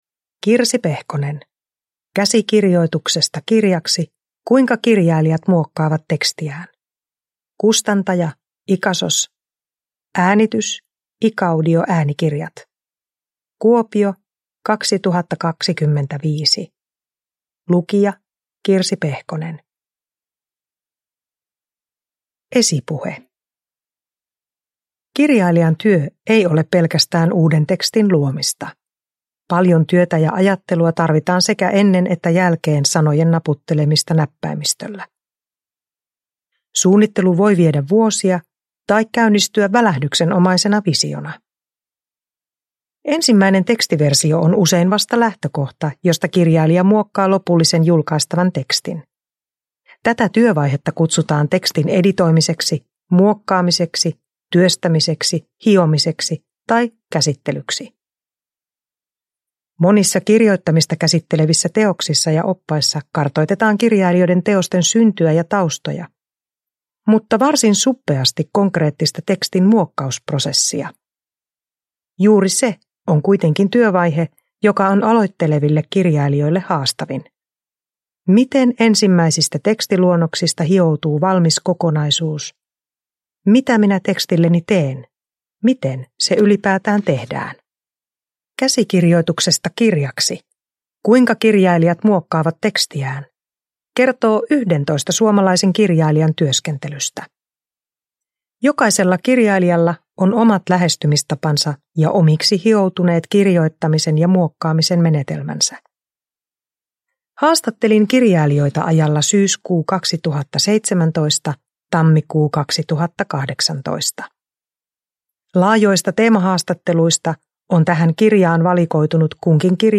Käsikirjoituksesta kirjaksi (ljudbok